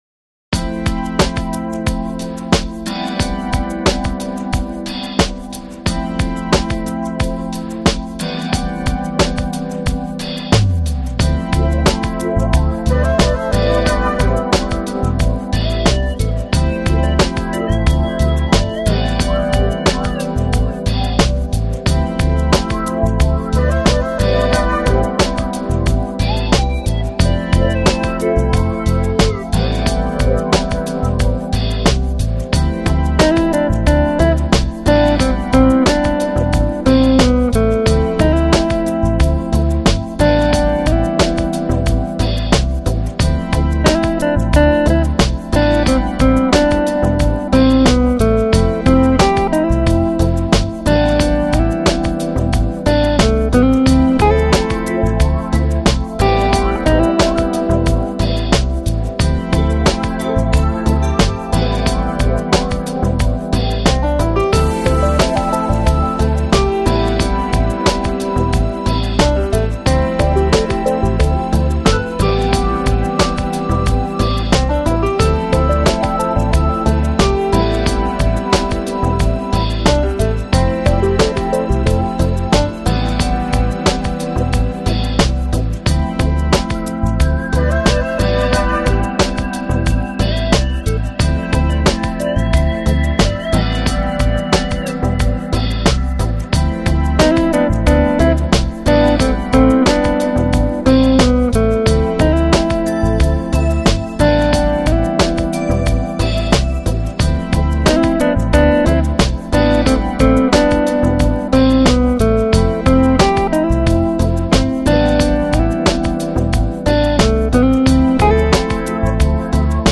Lounge, Chill Out, Smooth Jazz, Easy Listening Год издания